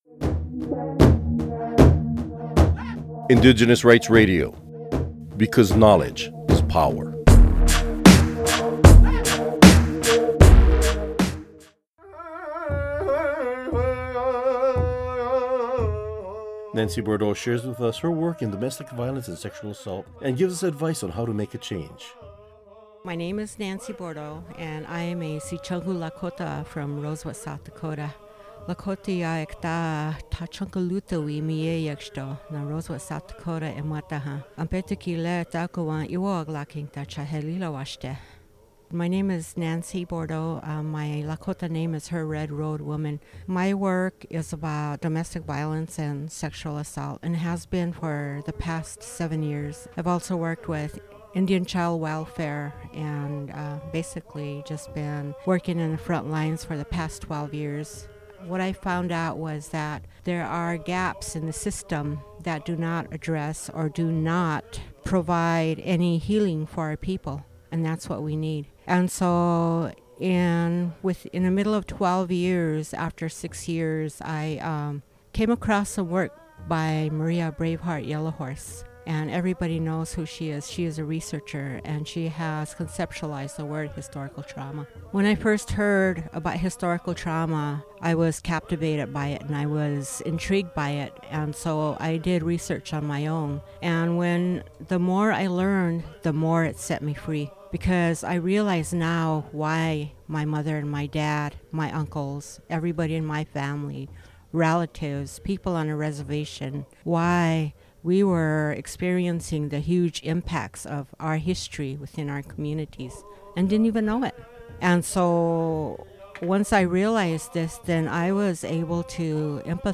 Recording Location: UNPFII 2015
Type: Interview
0kbps Stereo